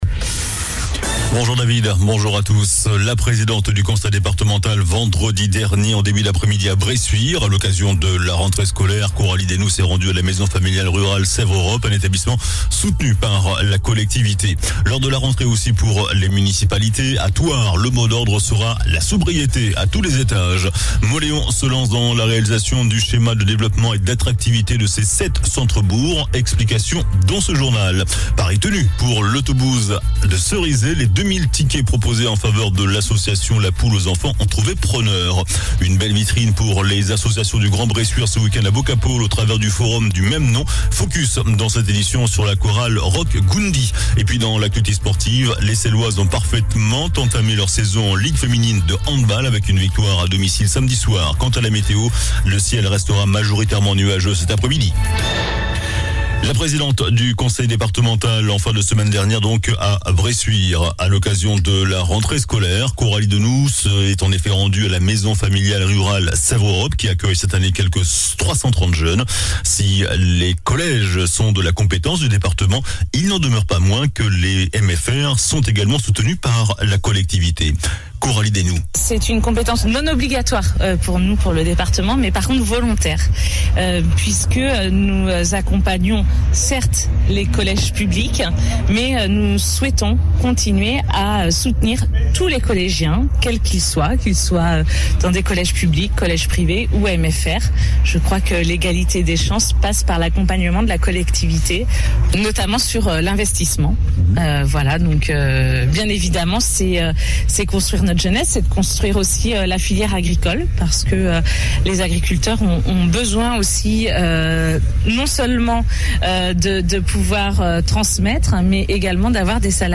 JOURNAL DU LUNDI 05 SEPTEMBRE ( MIDI )